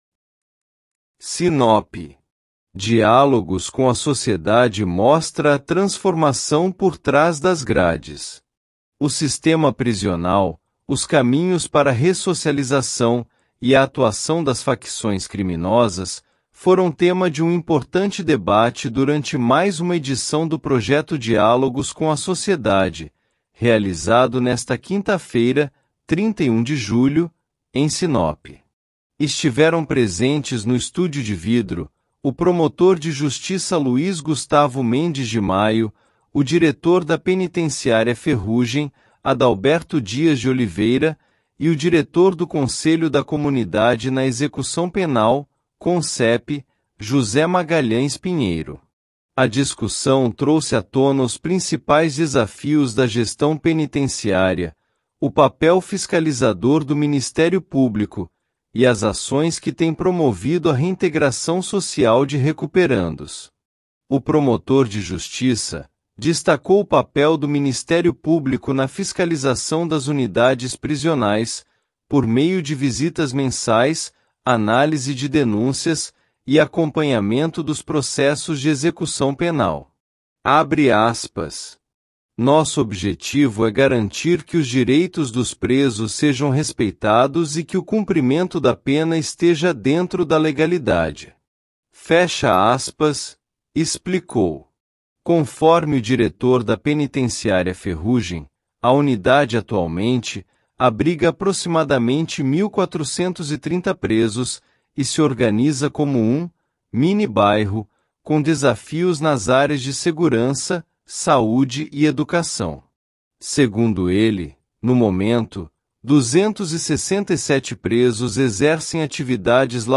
O sistema prisional, os caminhos para a ressocialização e a atuação das facções criminosas foram tema de um importante debate durante mais uma edição do projeto Diálogos com a Sociedade, realizado nesta quinta-feira (31 de julho), em Sinop.